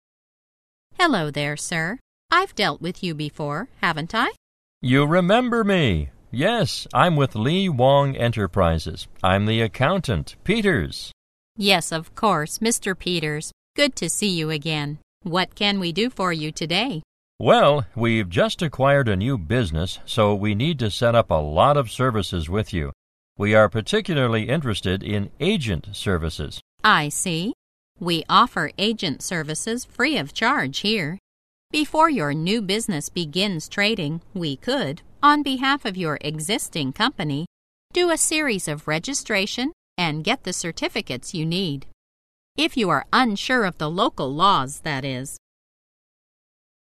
在线英语听力室银行英语情景口语 第89期:代理服务 代办公司业务(1)的听力文件下载, 《银行英语情景口语对话》,主要内容有银行英语情景口语对话、银行英语口语、银行英语词汇等内容。